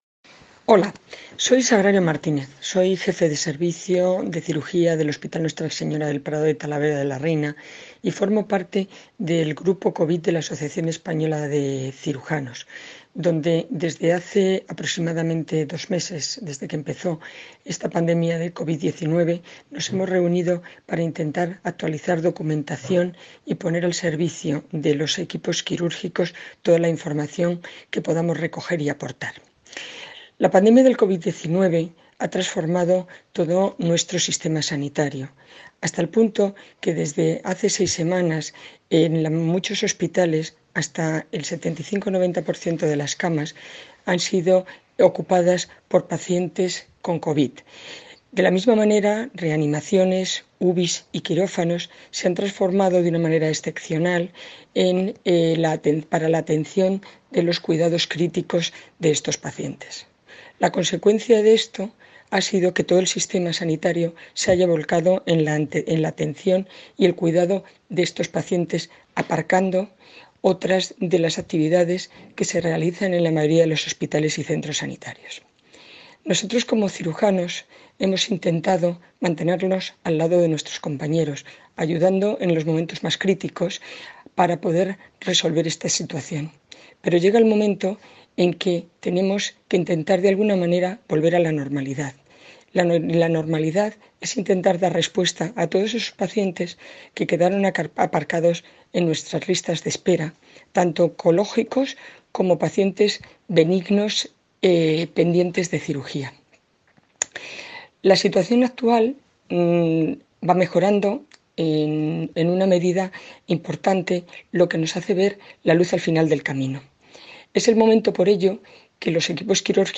Hoy en Buenos días hemos contado con diversos testimonios que nos hablan de la nueva fase que estamos viviendo en el estado de alarma.